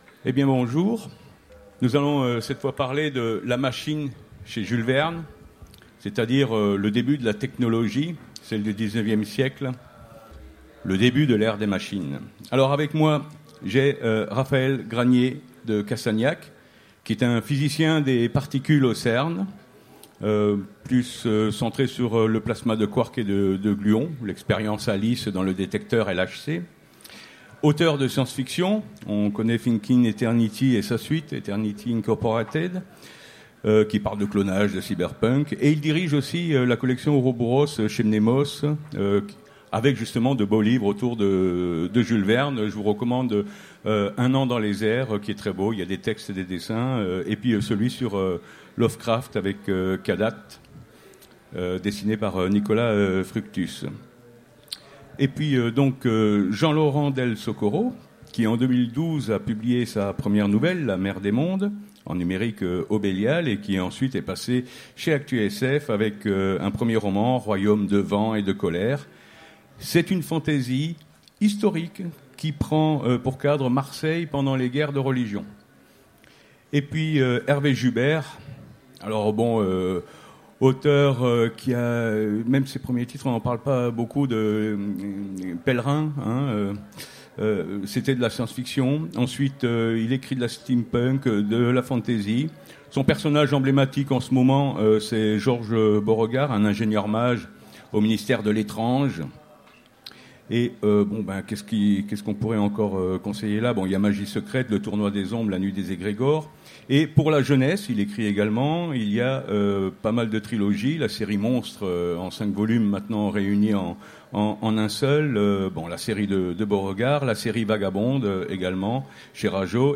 Utopiales 2016 : Conférence La machine chez Jules Verne